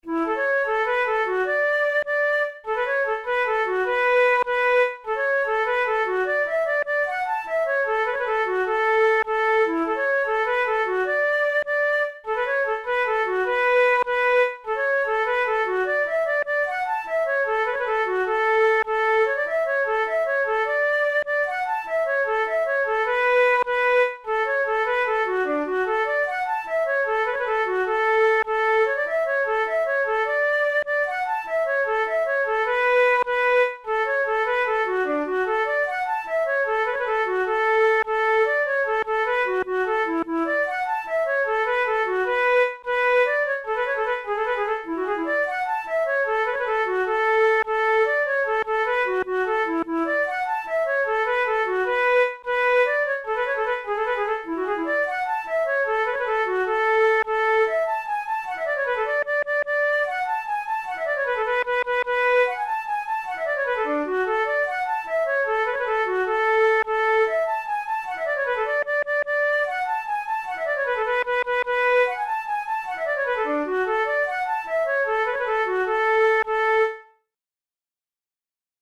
InstrumentationFlute solo
KeyA major
Time signature6/8
Tempo100 BPM
Jigs, Traditional/Folk
Traditional Irish jig